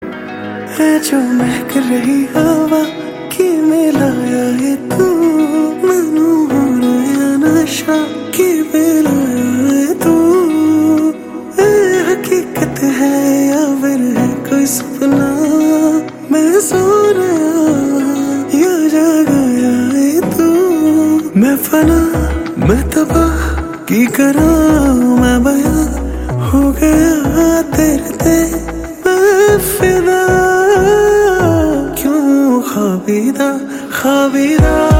romantic